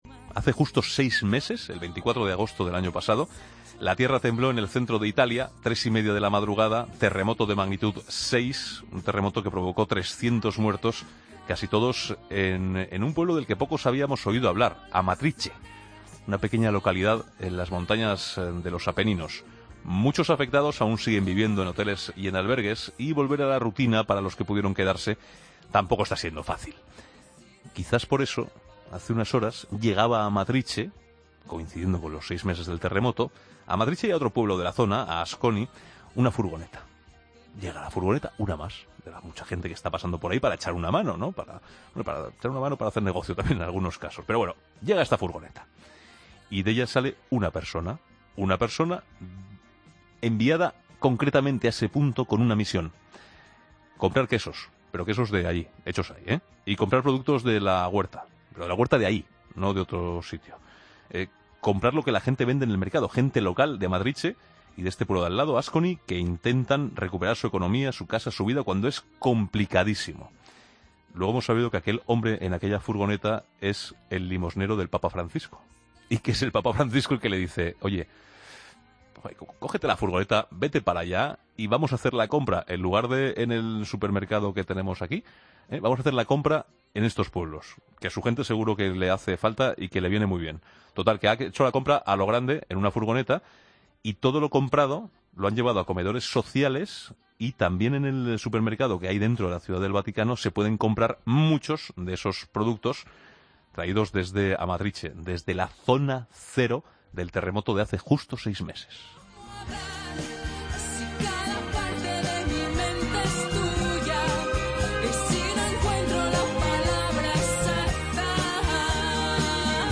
AUDIO: El obispo de San Sebastián, José Ignacio Munilla, ha expresado en 'La Tarde' su dolor ante la muerte de cinco mujeres en cuatro...